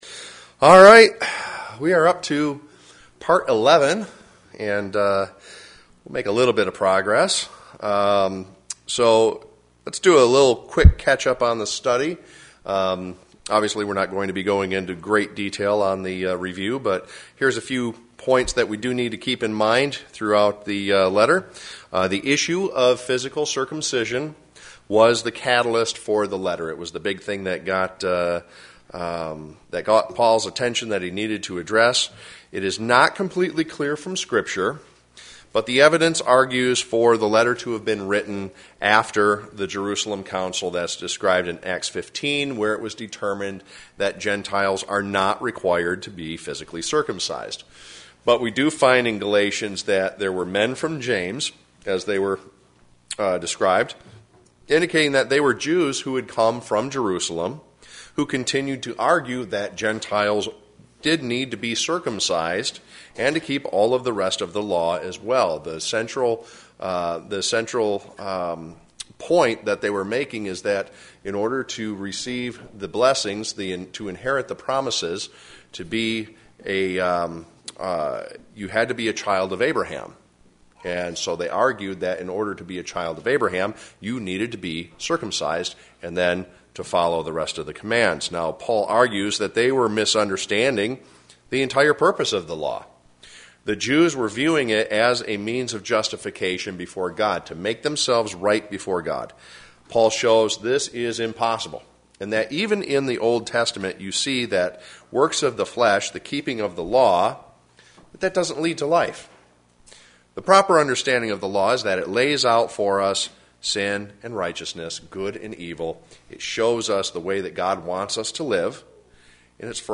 Galatians Bible Study: Part 11